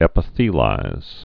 (ĕpə-thēlīz)